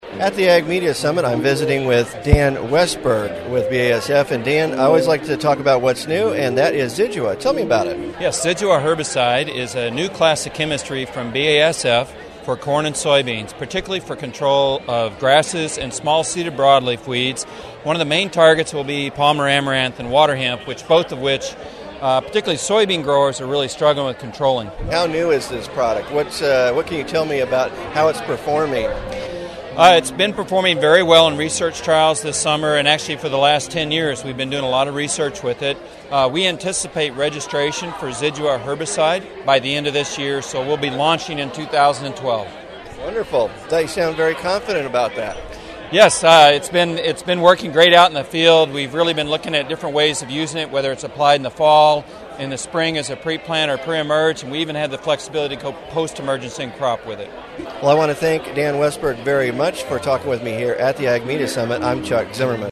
Meet three of my amigos from the Ag Media Summit.